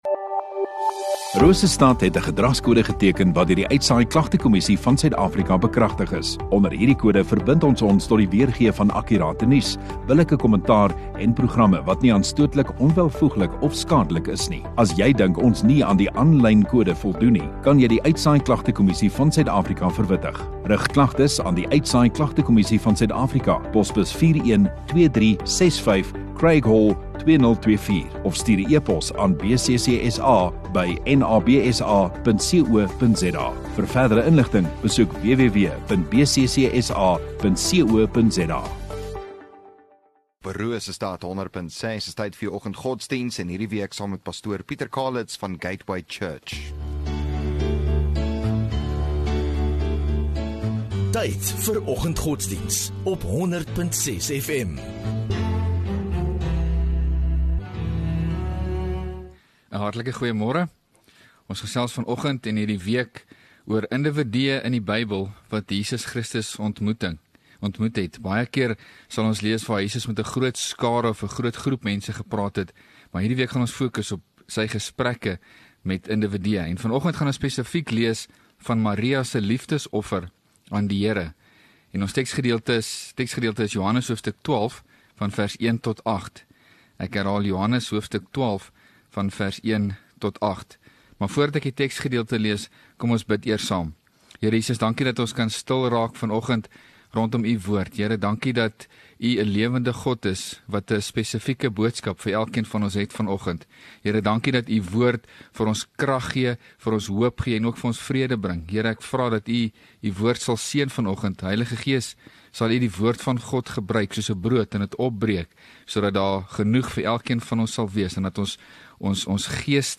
9 Sep Dinsdag Oggenddiens